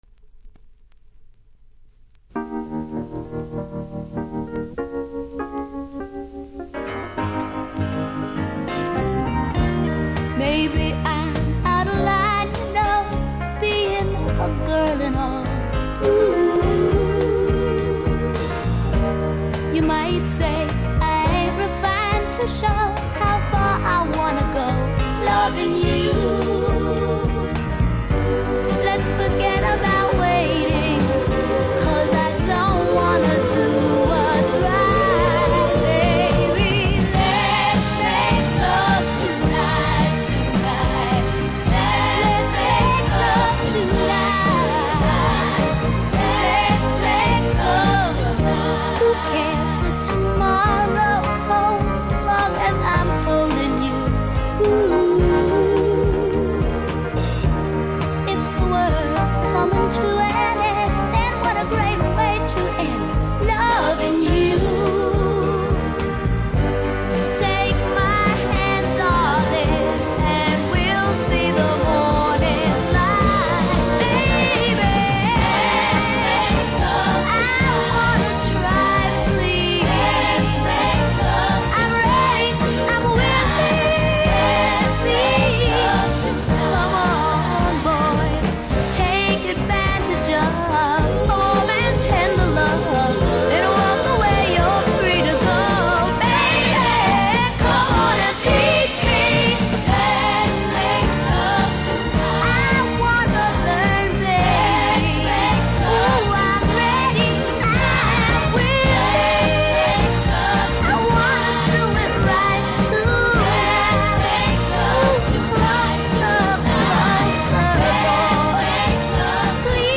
70'S FEMALE